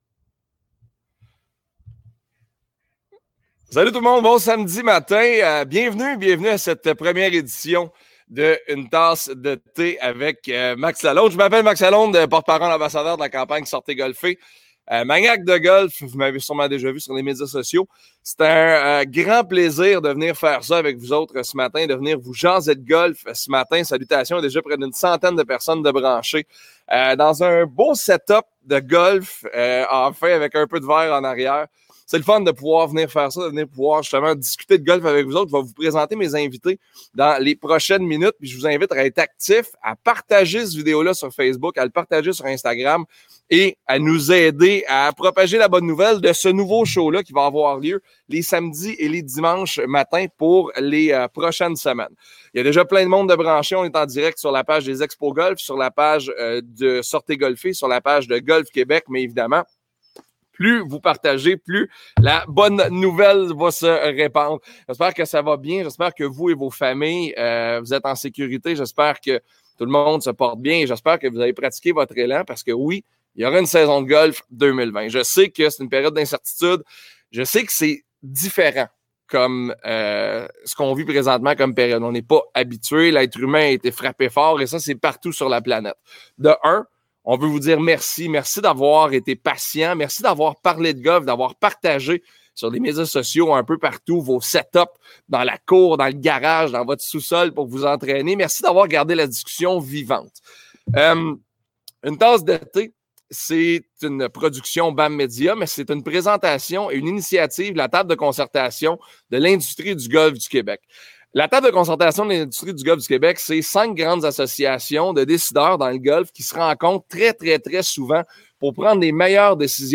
Discussion sur le travail de caddie et sur l'univers de cet emploi méconnu dans l'industrie du golf!